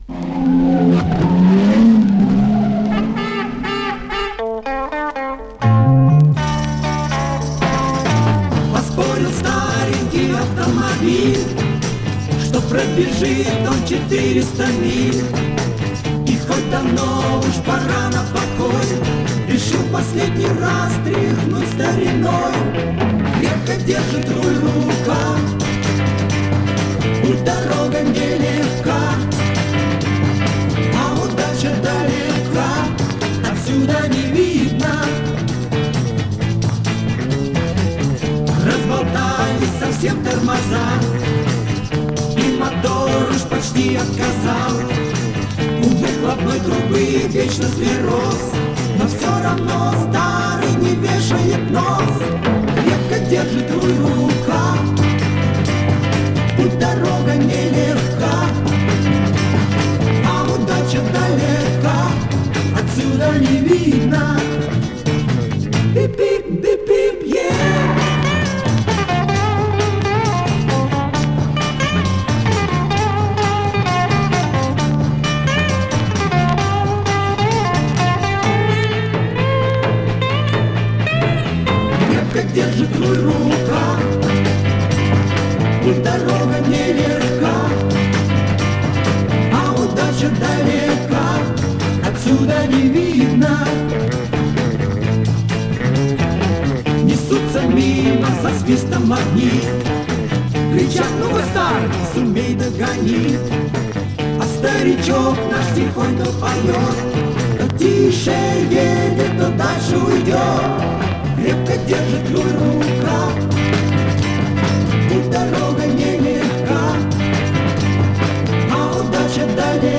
VOCAL-INSTRUMENTAL ENSEMBLE
Mono